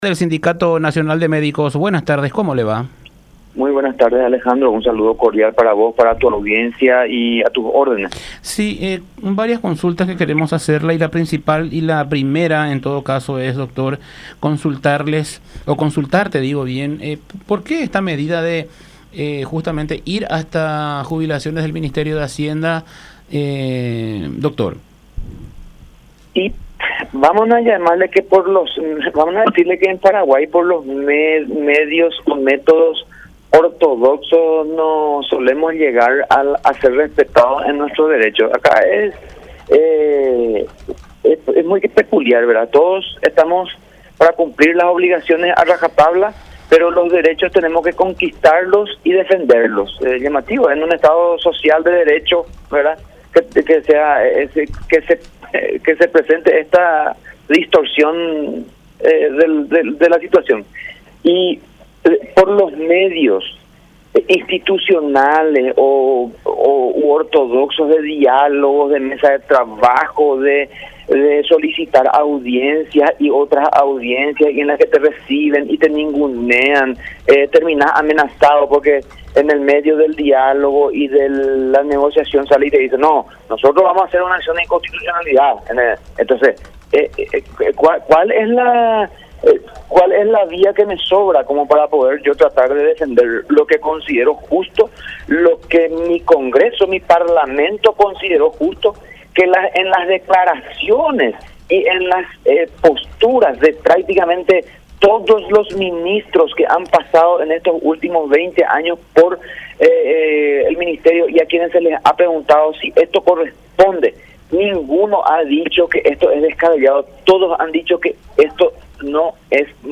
en comunicación con la Unión R800 AM.